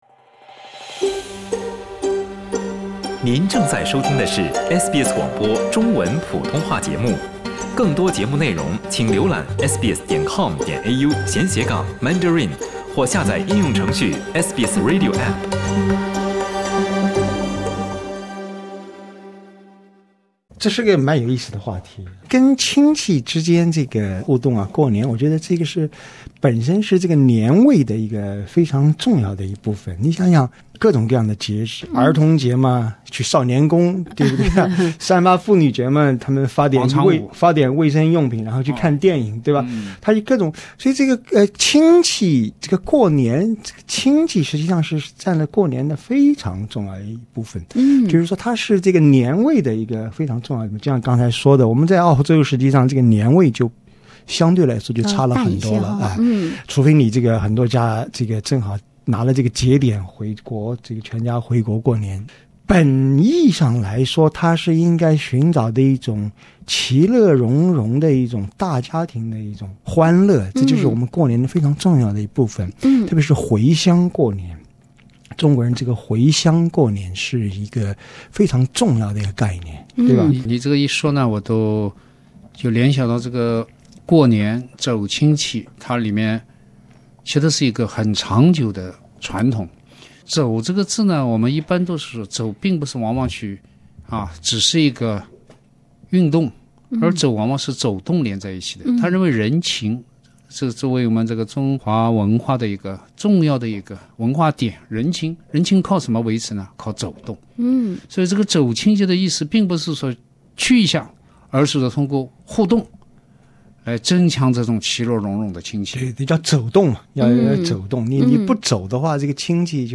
逢年过节被人追着“关怀”，有时也并不一定是件好事。（点击封面图片，收听完整对话）